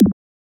select-expand.wav